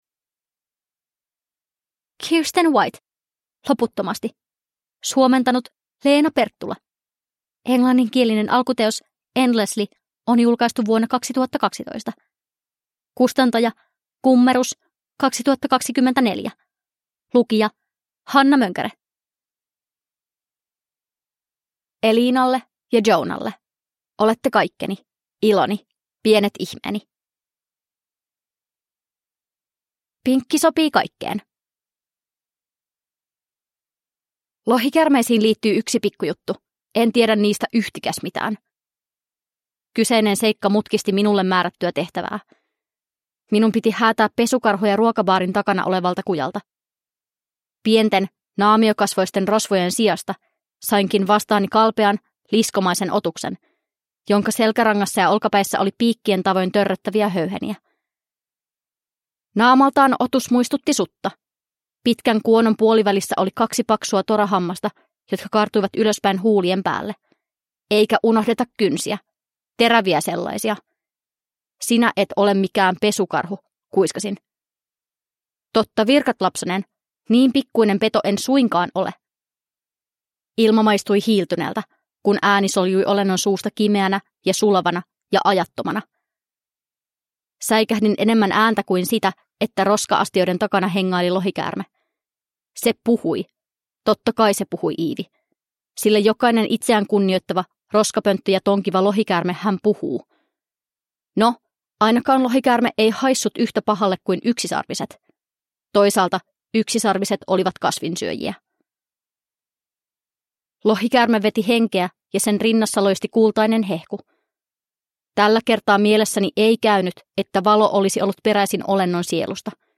Loputtomasti – Ljudbok